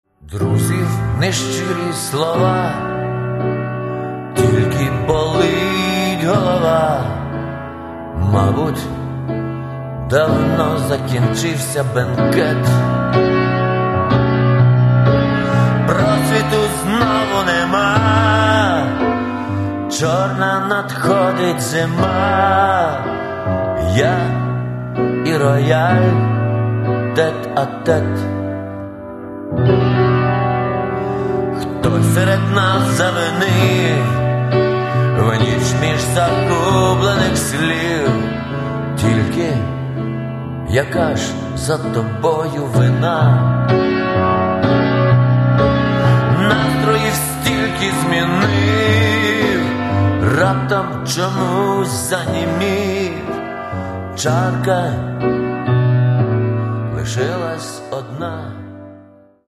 Каталог -> Рок и альтернатива -> Лирический андеграунд
Иронично, грустно, жестко, просто улыбчиво – здесь есть все.